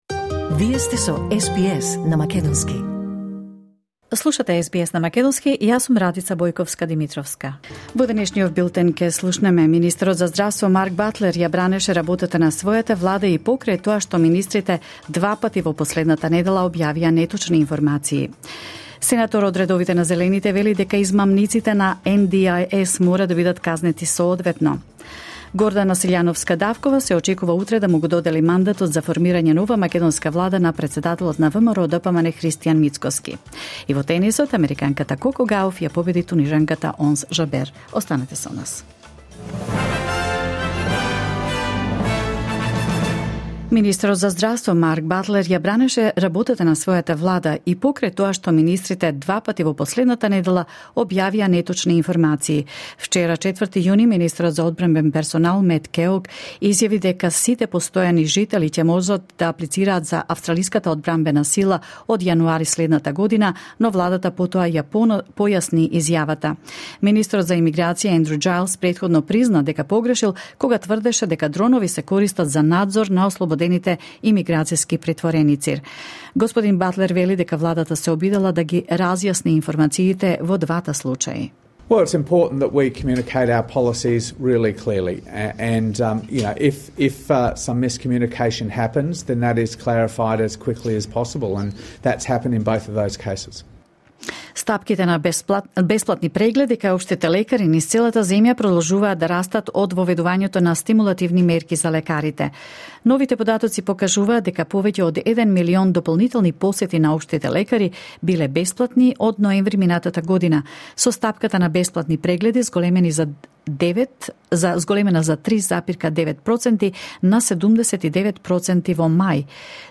Вести на СБС на македонски 5 јуни 2024